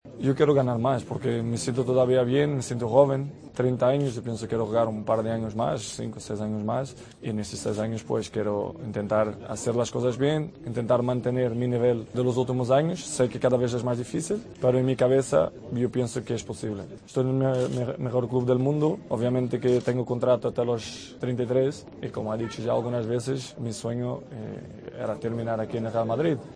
El crack luso volvió a afirmar durante una entrevista a Marca que es muy feliz en el Madrid y sueña con retirarse de blanco.